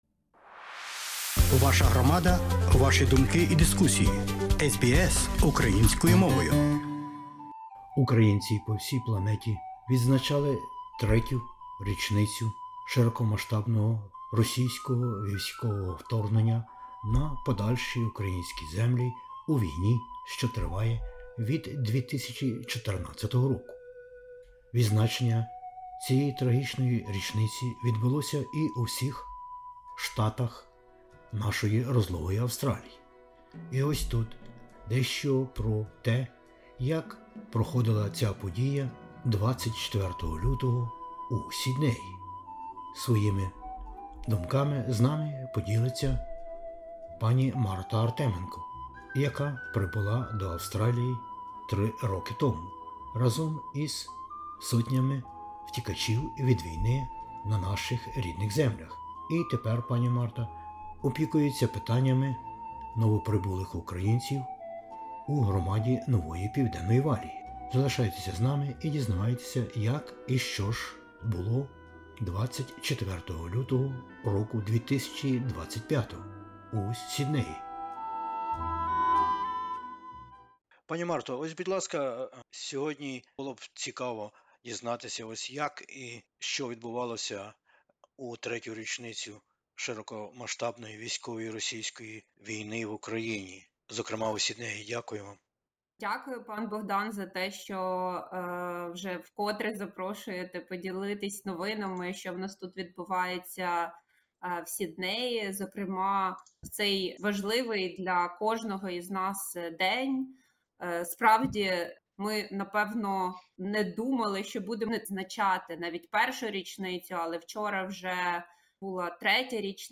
У розмові з SBS Ukrainian